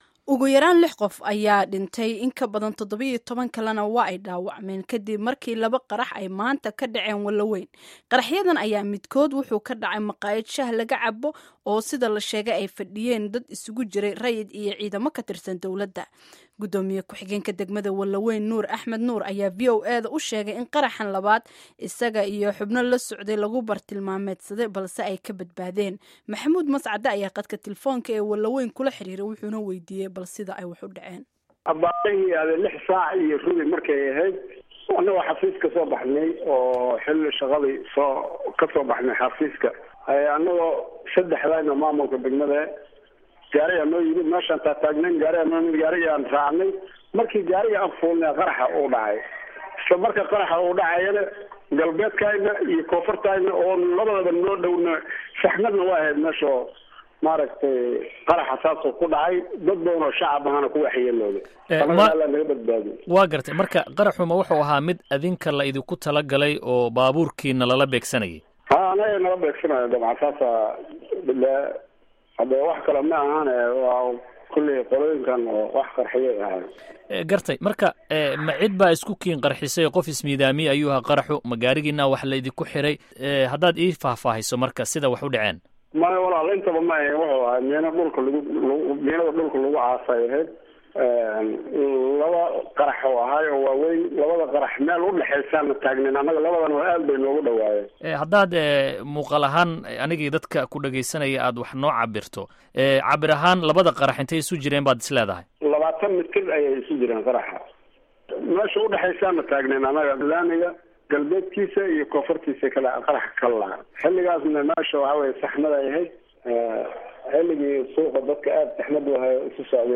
Wareysiga G. Ku-xigeenka Wanlaweyn